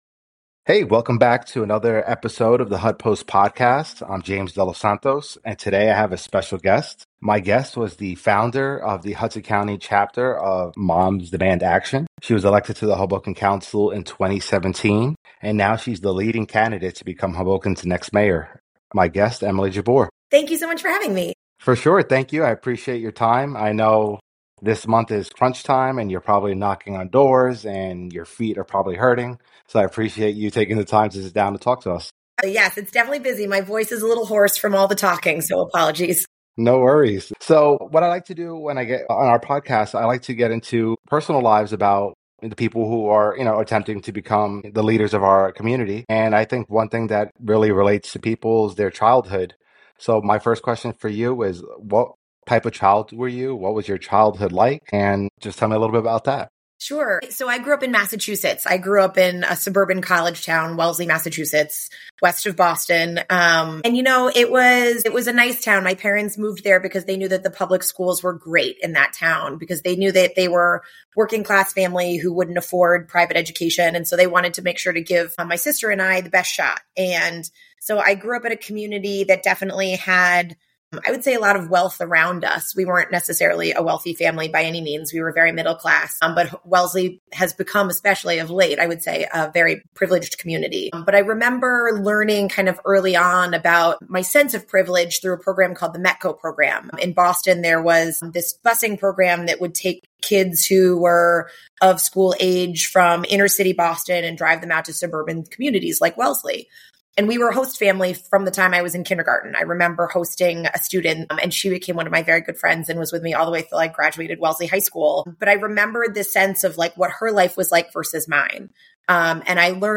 Interview with Emily Jabbour